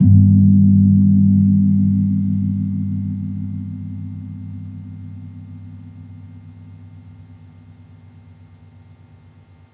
Gong-G1-p.wav